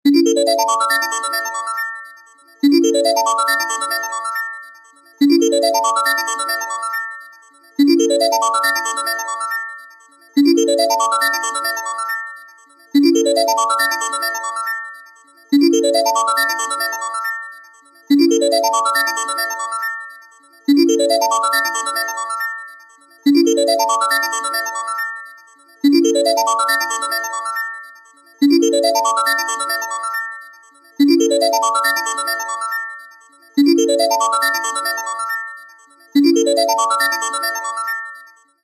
魅力的な魔法の要素と、繰り返し再生されるループの優れた組み合わせです。